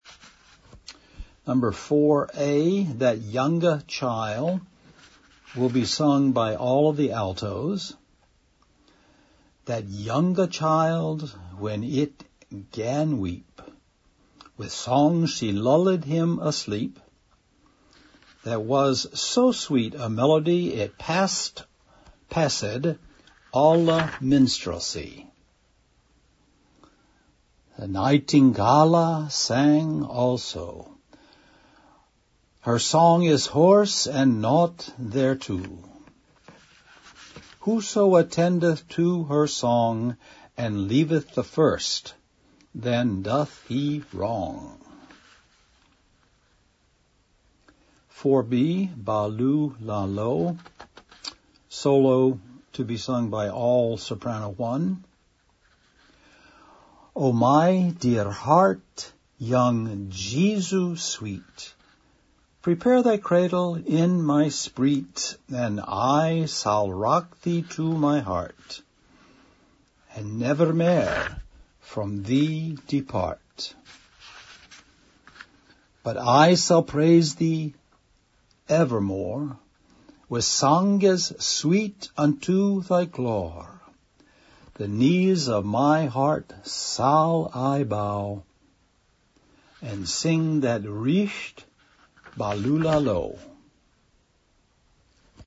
Pronunciation files for Britten
04-a-b-movements-Britten-Pronunciation.mp3